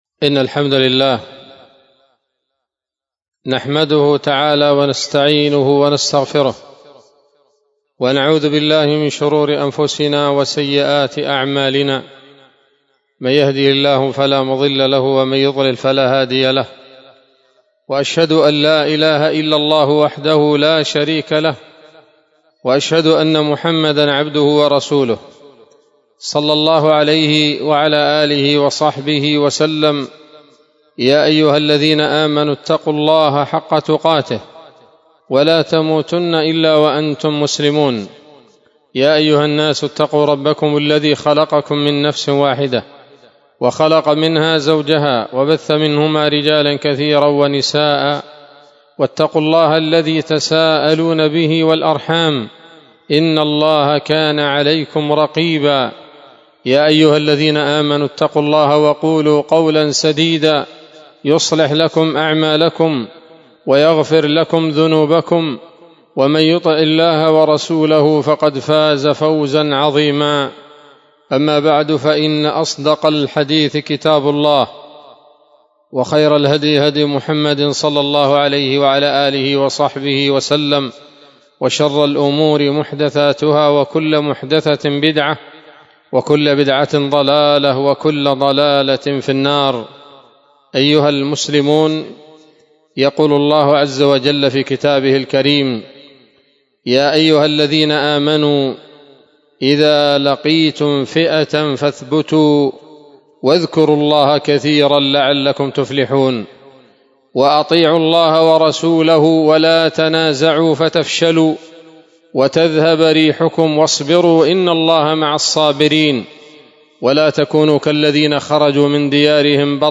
خطبة جمعة بعنوان: (( النصر المبين من رب العالمين )) 14 جمادى الآخرة 1447 هـ، دار الحديث السلفية بصلاح الدين